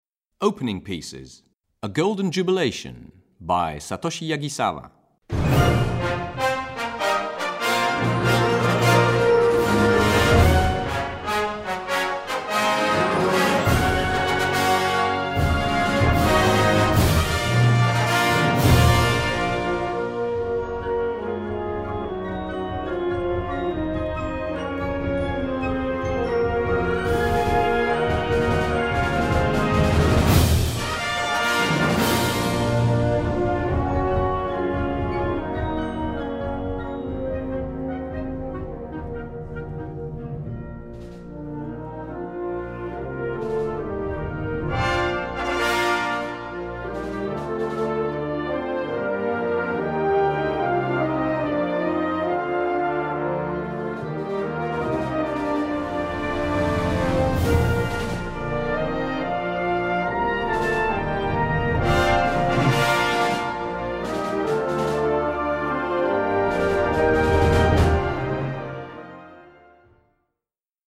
Gattung: Eröffnungswerk
Besetzung: Blasorchester